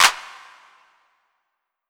Metro Clap 11 Wet.wav